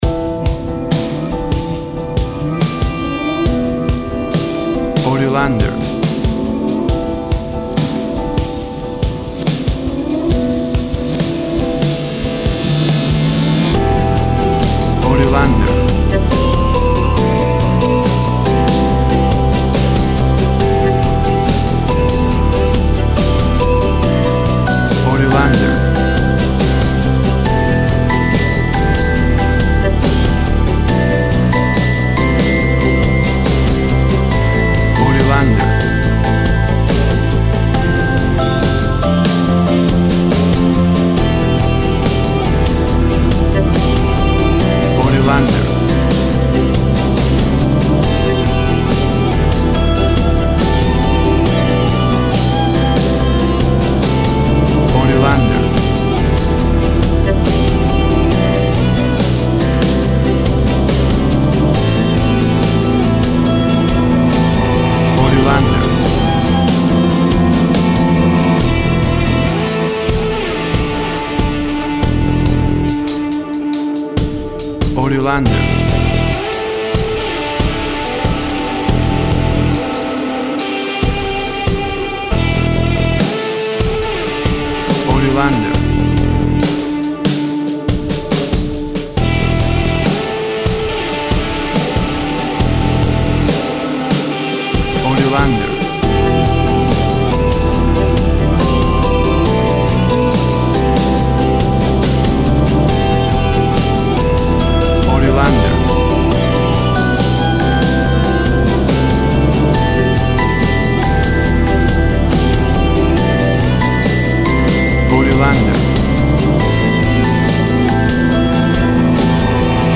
Drama and nostalgic ambient rock sounds.
Tempo (BPM) 70